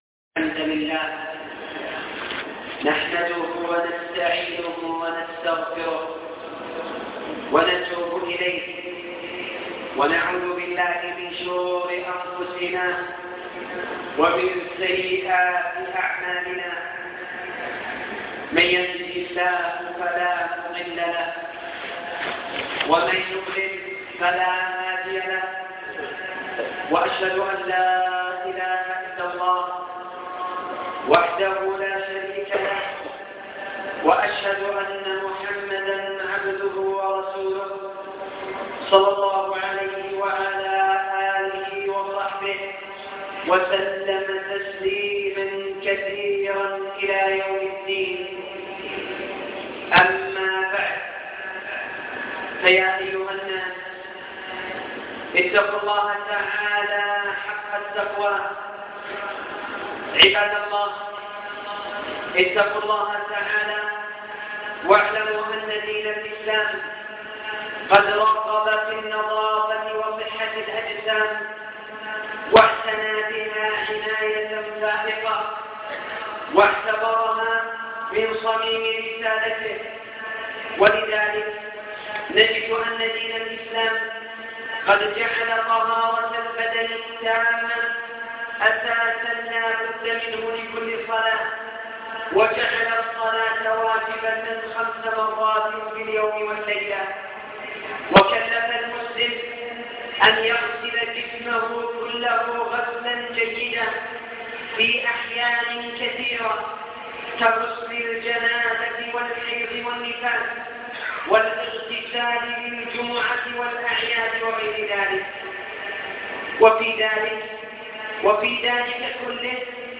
خطبة عن قيادة المرأة للسيارة